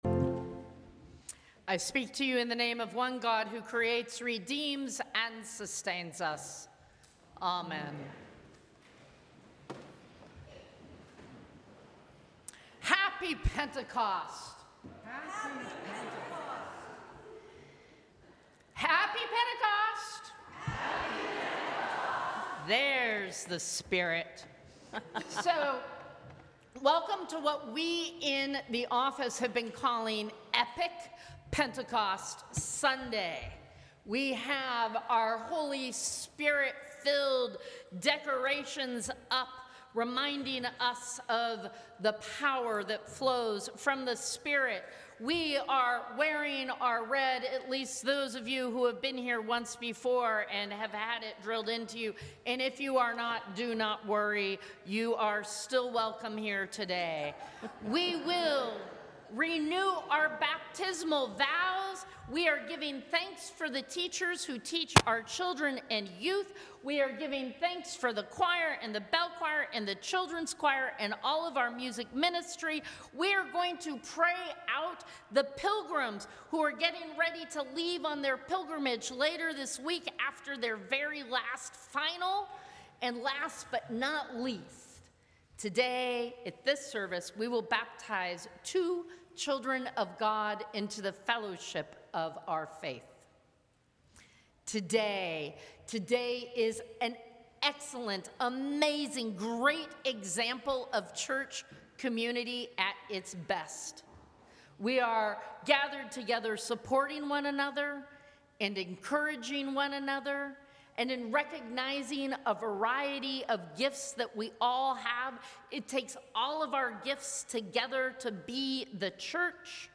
Sermons from St. Cross Episcopal Church Day of Pentecost Jun 08 2025 | 00:11:10 1x 00:00 / 00:11:10 Subscribe Share Apple Podcasts Spotify Overcast RSS Feed Share Link Embed